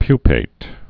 (pypāt)